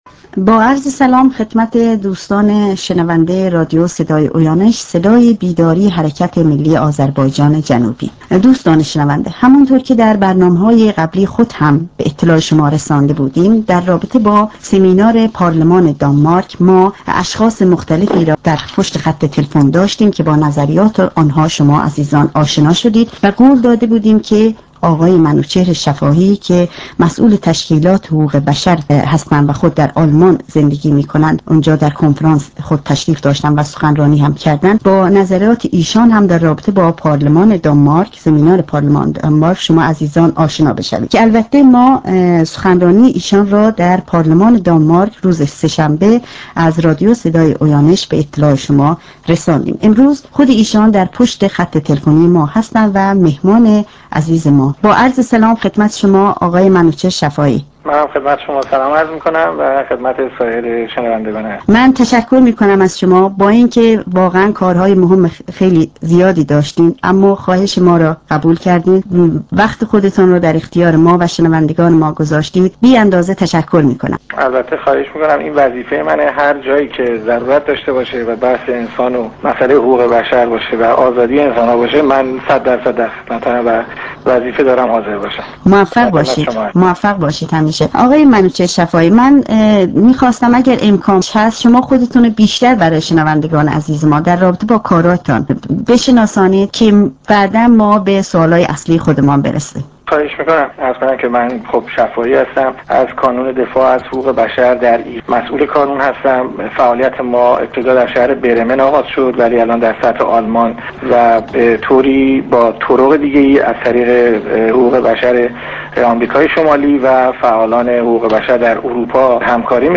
مصاحبه تلفنی ، پرسش و پاسخ با شنوندگان رادیو اویانوش